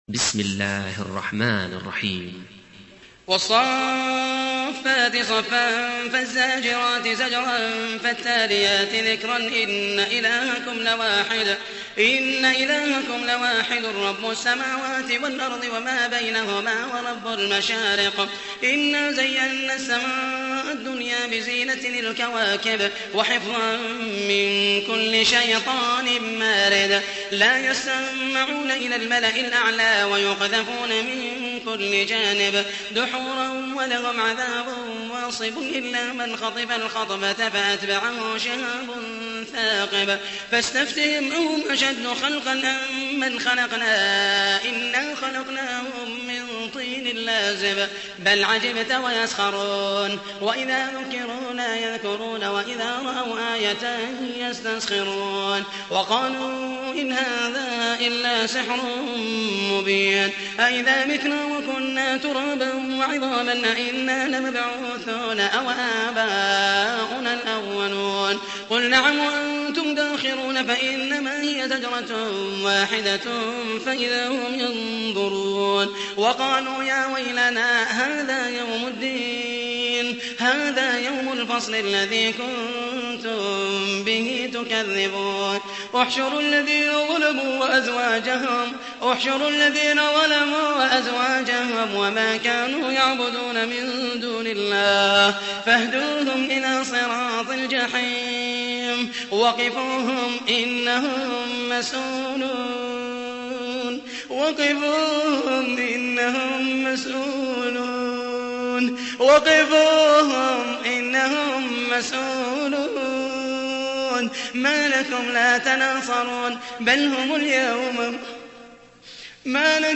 تحميل : 37. سورة الصافات / القارئ محمد المحيسني / القرآن الكريم / موقع يا حسين